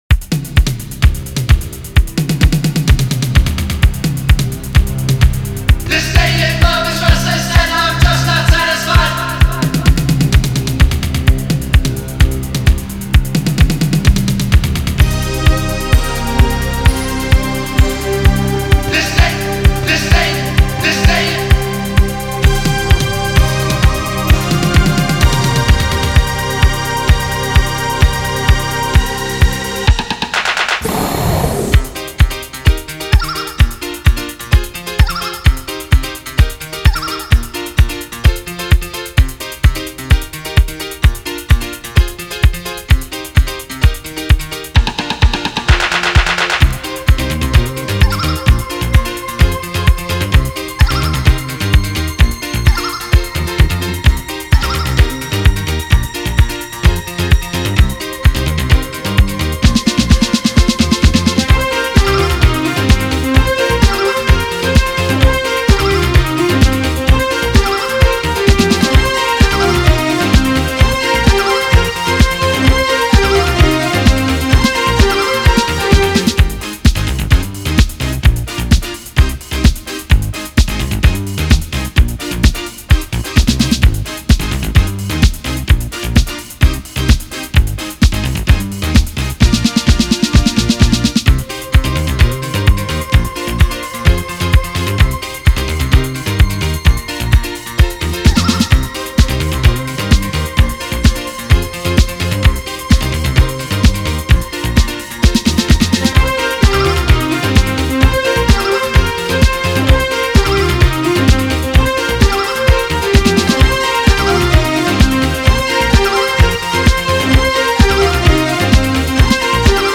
Four groovers of Cosmic, Disco and Italo adventures for all.